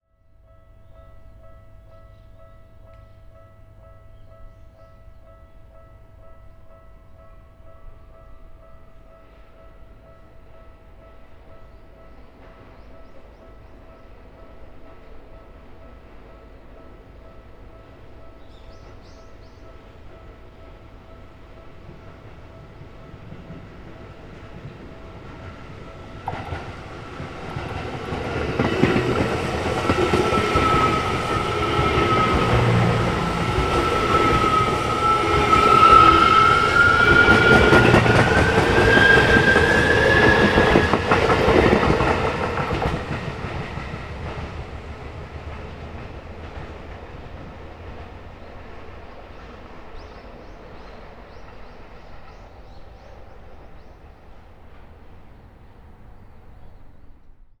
続いて、線路脇に行って、踏切のそばで電車が通り過ぎる音を捉えた。これも同じく、Sound Forgeで調整の上、24bitにしている。19.4mmの口径のマイクを使っているからか、とくに目の前を電車が通り過ぎる際の迫力がかなり感じられるはずだ。
録音サンプル「電車」
H5studio_train_2496.wav